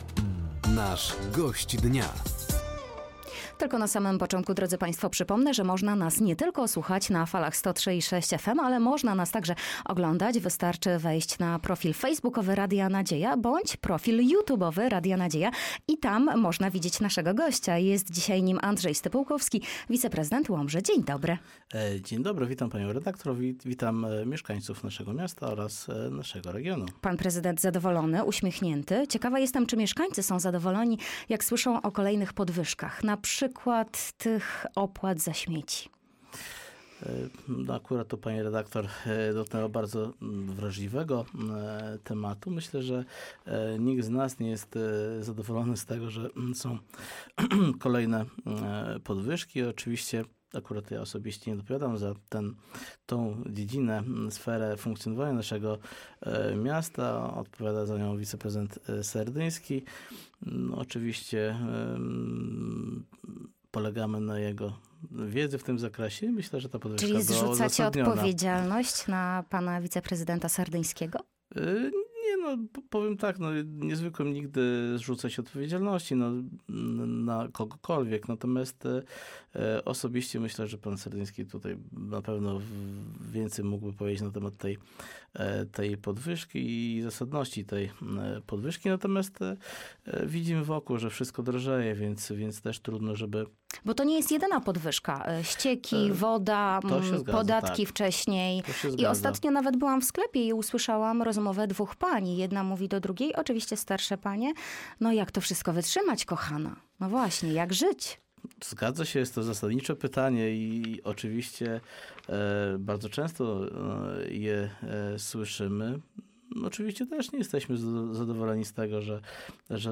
O podwyżkach opłat za śmieci, większej liczbie punktów sprzedaży alkoholu w mieście, czy sprzeciwie wobec utworzenia w Łomży Centrum Integracji Cudzoziemców – mówił na antenie Radia Nadzieja Gość Dnia, którym był Andrzej Stypułkowski.
Zapraszamy do wysłuchania i obejrzenia rozmowy.